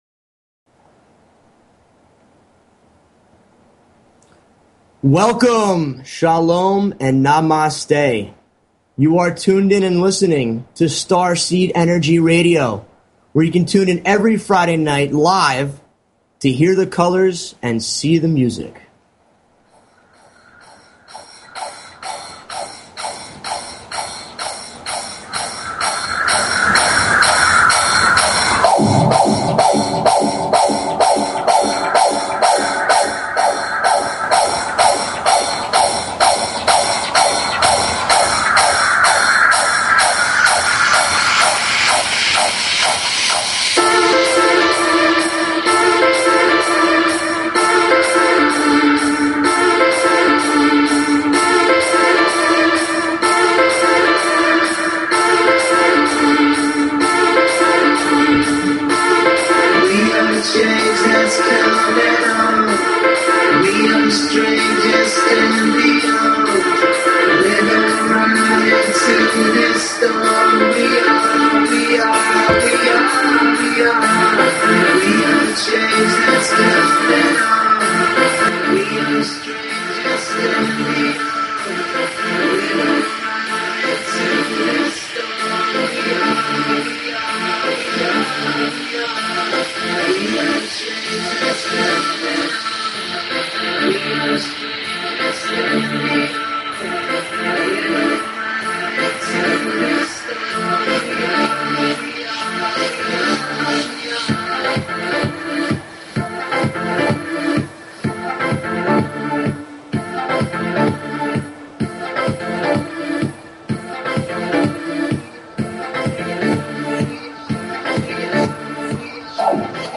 Talk Show Episode, Audio Podcast, Starseed_Energy_Radio and Courtesy of BBS Radio on , show guests , about , categorized as
ELECTRO & HOUSE MUSIC TO LIFT YOUR VIBRATION Starseed Energy Radio Please consider subscribing to this talk show.